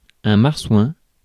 Ääntäminen
Synonyymit cochon de mer Ääntäminen France: IPA: /maʁ.swɛ̃/ Haettu sana löytyi näillä lähdekielillä: ranska Käännös Substantiivit 1. морска свиня Suku: m .